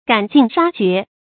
赶尽杀绝 gǎn jìn shā jué
赶尽杀绝发音
成语正音 尽，不能读作“jǐn”。